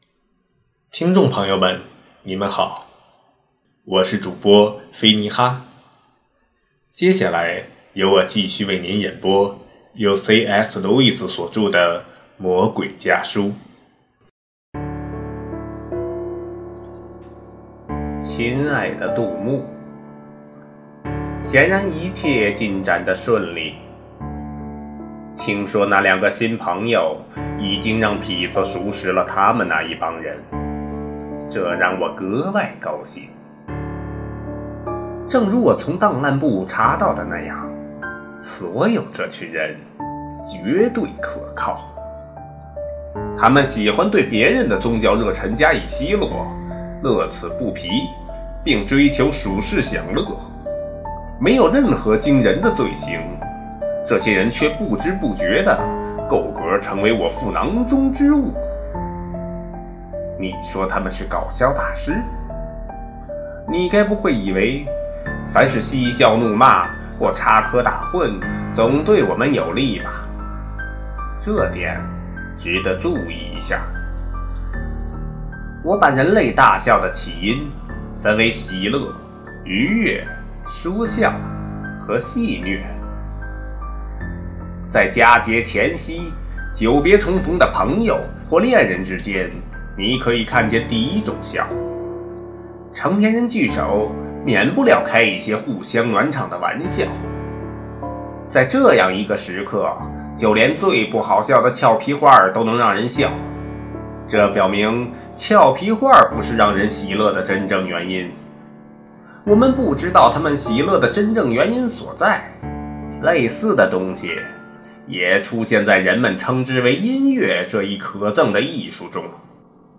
首页 > 有声书 | 灵性生活 | 魔鬼家书 > 魔鬼家书：第十一封书信